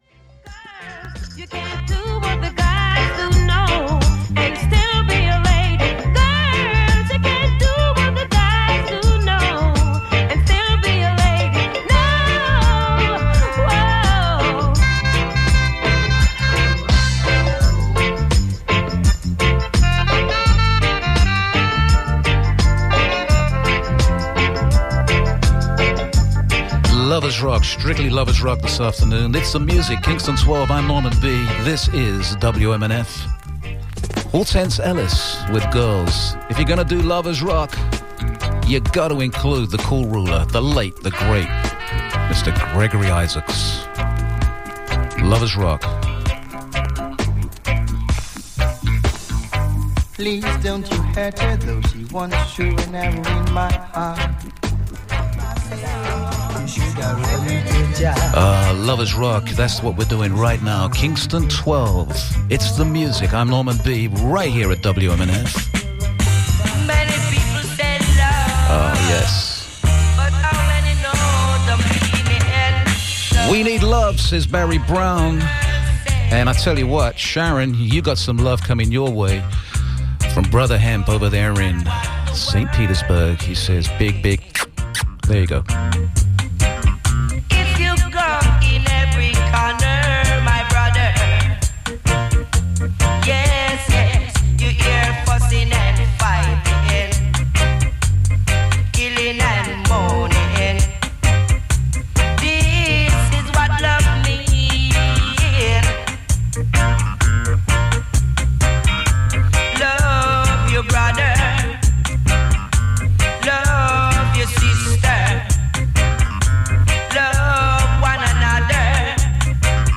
lovers-rock-sample.wav